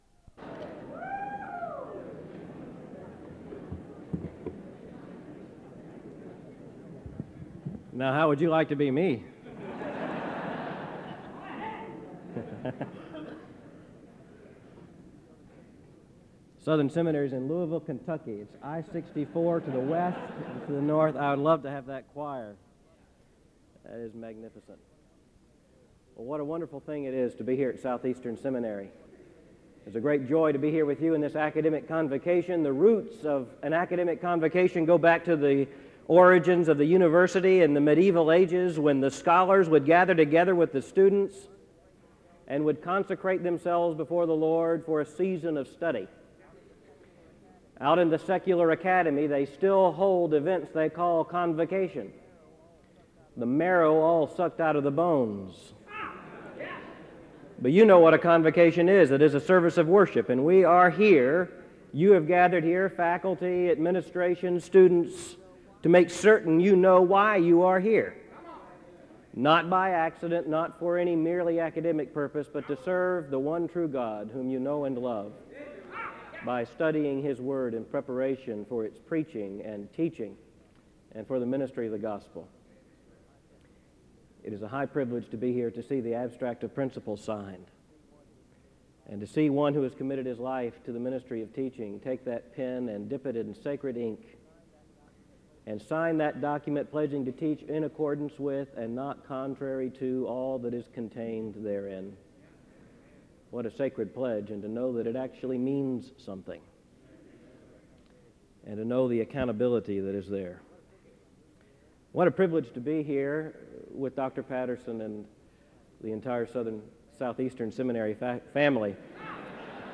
SEBTS_Convocation_R_Albert_Mohler_Jr_1998-01-27.wav